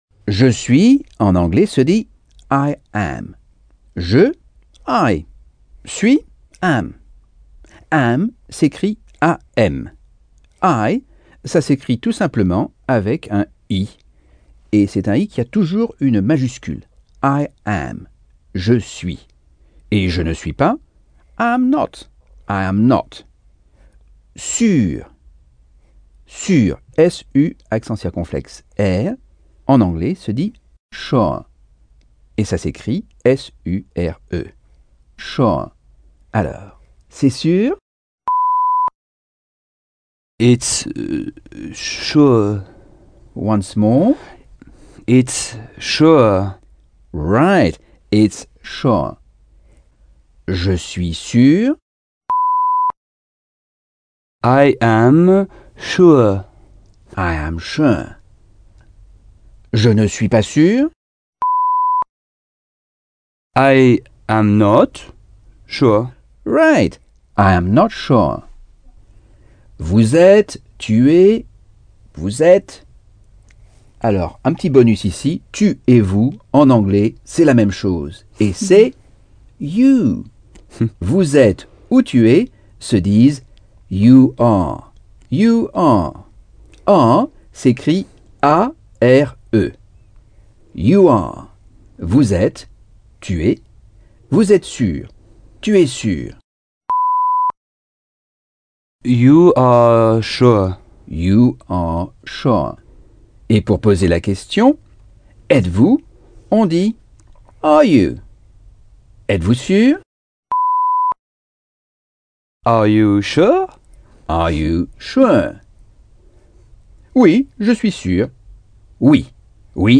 Leçons 6 - Anglais audio par Michel Thomas